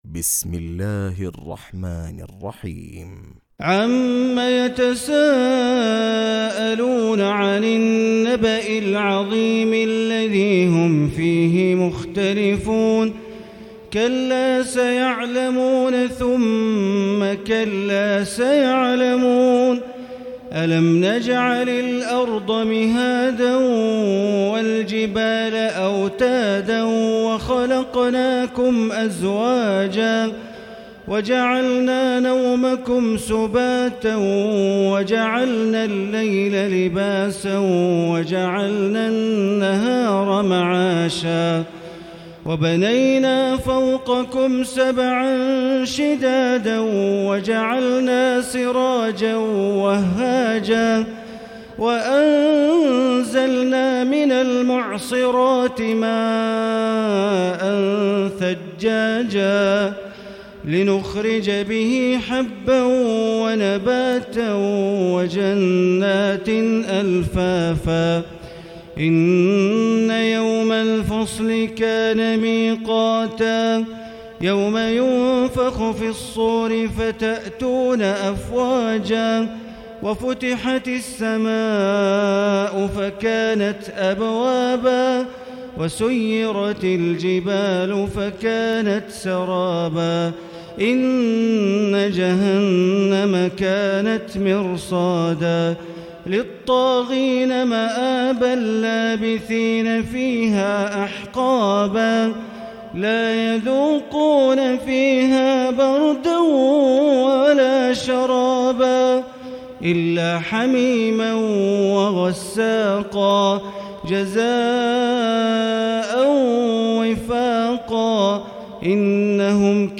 تراويح ليلة 29 رمضان 1439هـ من سورة النبأ الى البينة Taraweeh 29 st night Ramadan 1439H from Surah An-Naba to Al-Bayyina > تراويح الحرم المكي عام 1439 🕋 > التراويح - تلاوات الحرمين